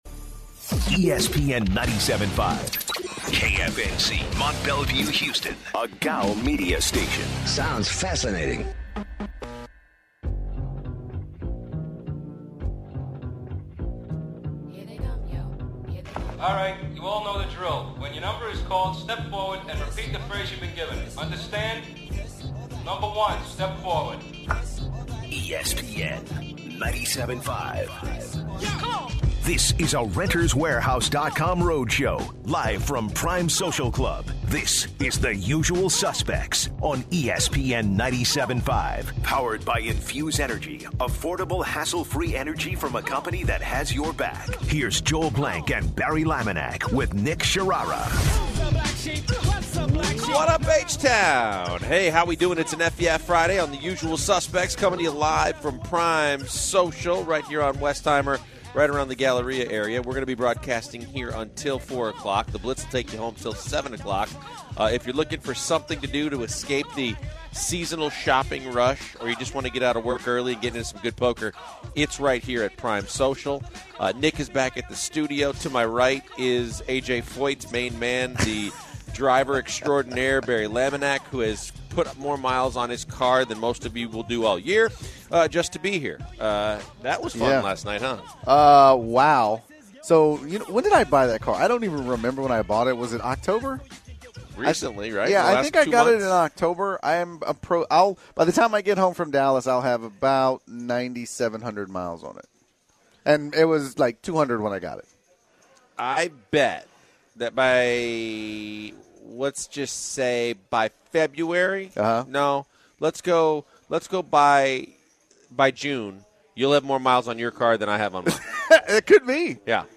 on location at Prime Social